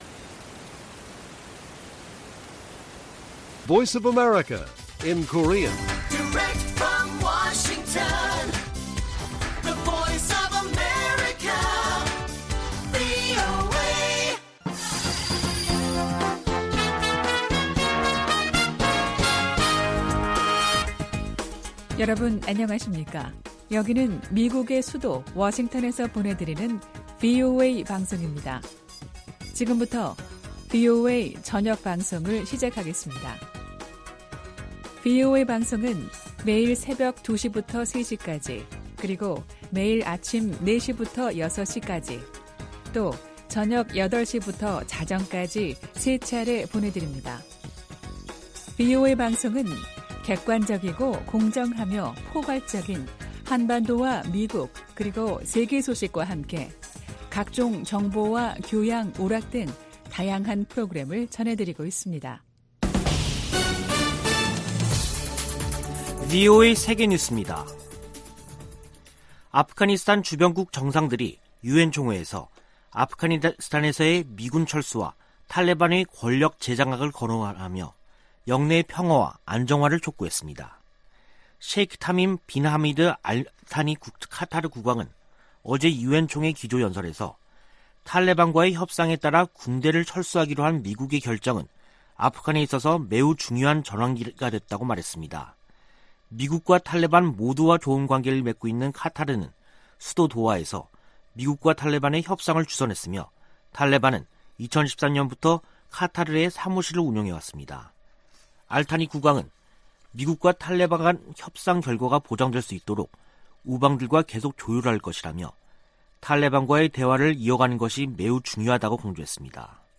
VOA 한국어 간판 뉴스 프로그램 '뉴스 투데이', 2021년 9월 22일 1부 방송입니다. 조 바이든 미국 대통령이 한반도 완전 비핵화를 위해 지속적 외교와 구체적 진전을 추구한다고 밝혔습니다. 문재인 한국 대통령은 종전선언을 제안했습니다. 미 연방수사국(FBI)이 북한의 사이버 역량 증대를 지적했습니다.